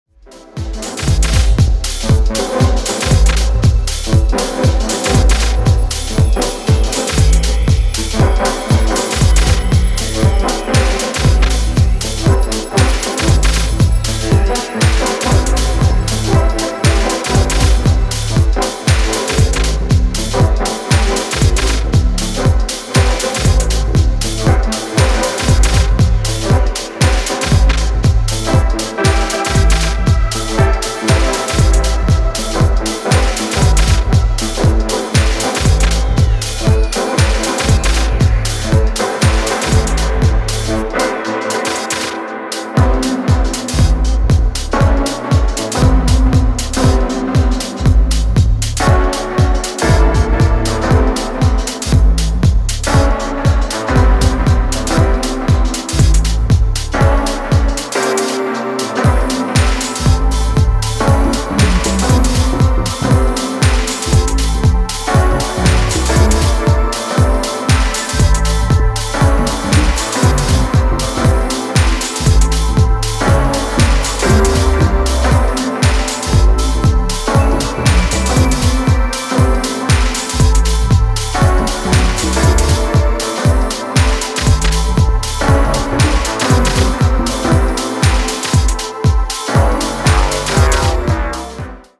空間的なシンセ・リフを際立たせながら、よりディープで幻想的なビートダウン・ハウスへと仕立てています。